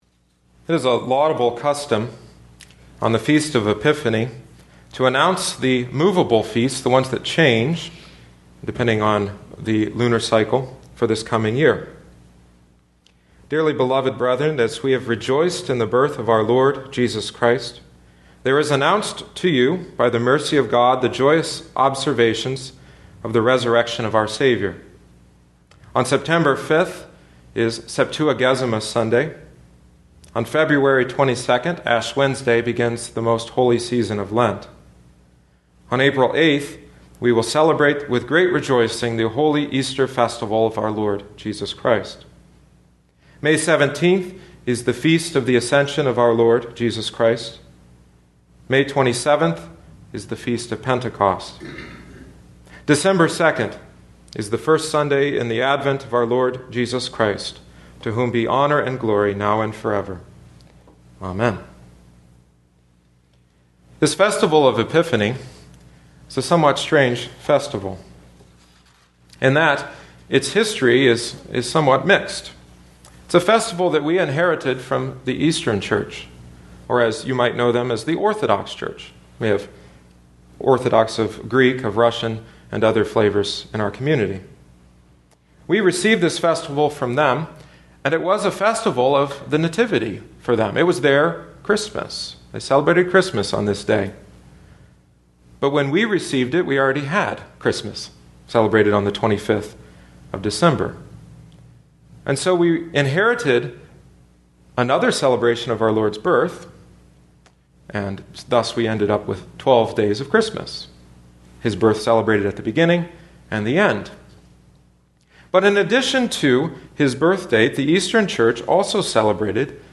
These questions continue to be asked by the Gentile world. Learn the answer in tonight’s sermon.